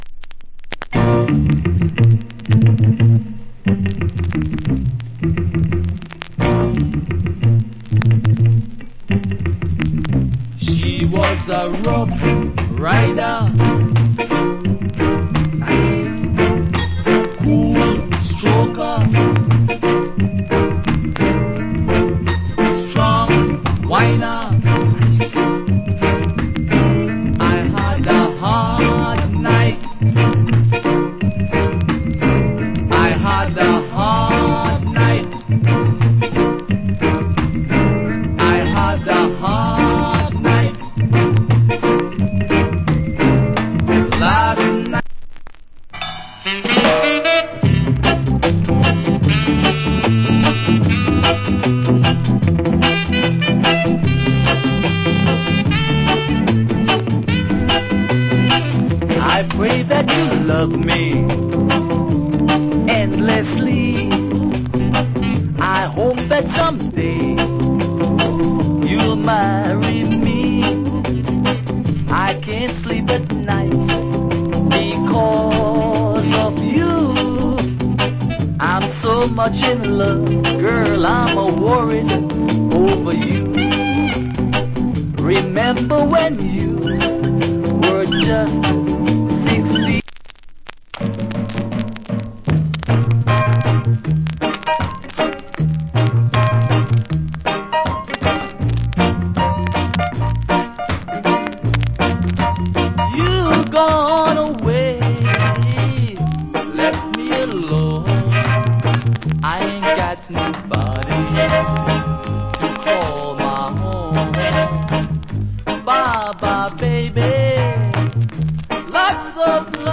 Great Selection Rock Steady To Early Reggae.